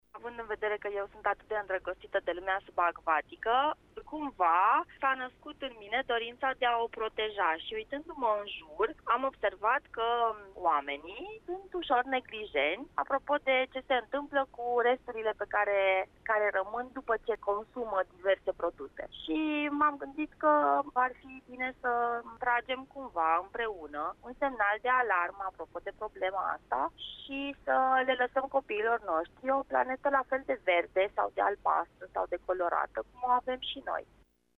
ne-a vorbiţ la Radio Constanţa despre cum a pornit această campanie